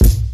kits/Southside/Kicks/Southside SK (23).wav at main